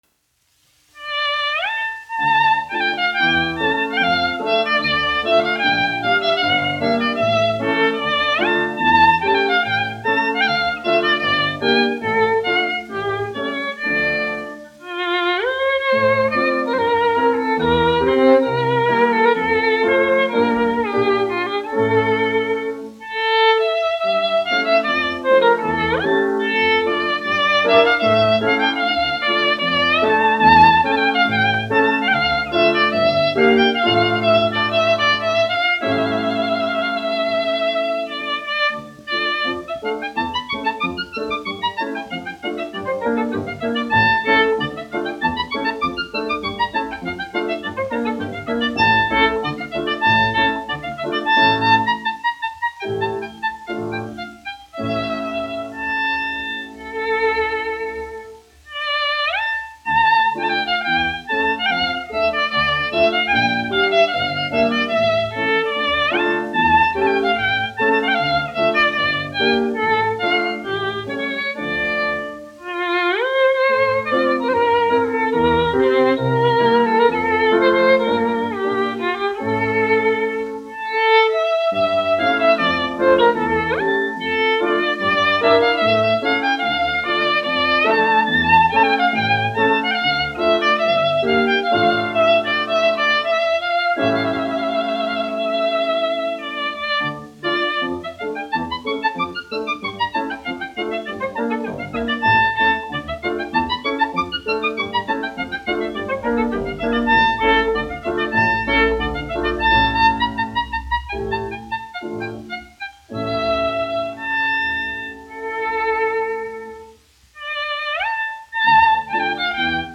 1 skpl. : analogs, 78 apgr/min, mono ; 25 cm
Vijoles un klavieru mūzika, aranžējumi
Latvijas vēsturiskie šellaka skaņuplašu ieraksti (Kolekcija)